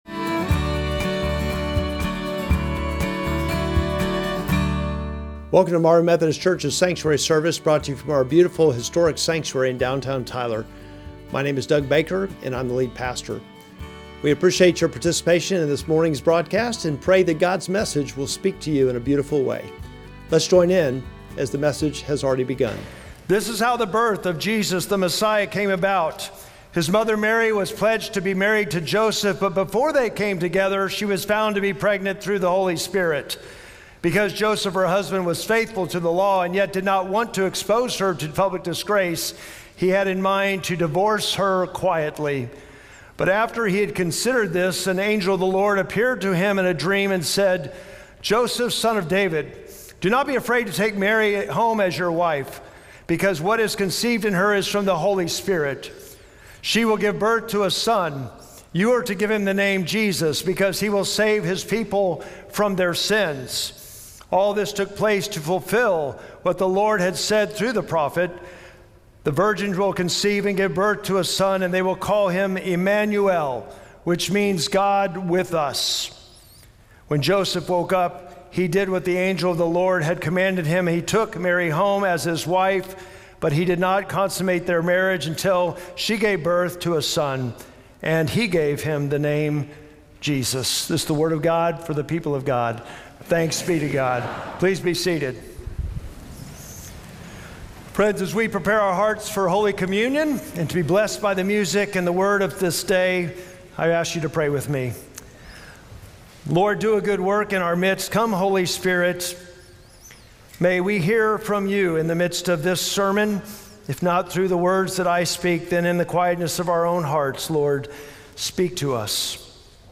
Sermon text: Matthew 1:18-25